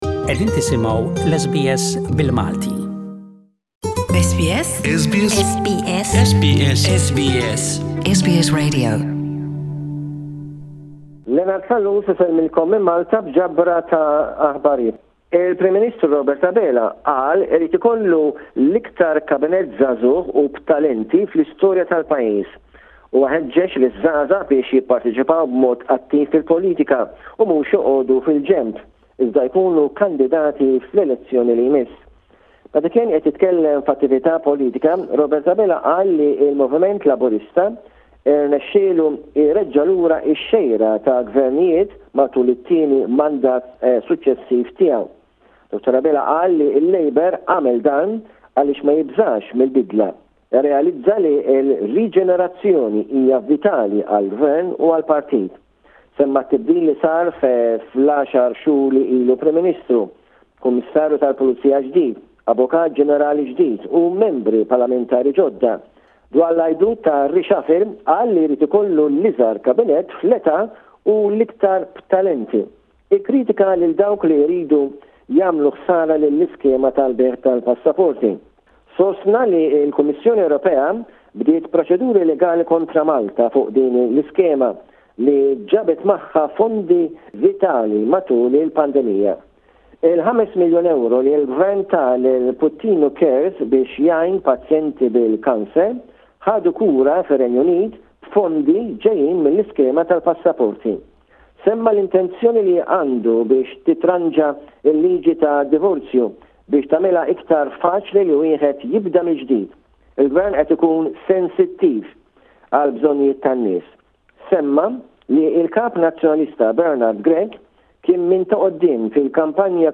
Malta: political report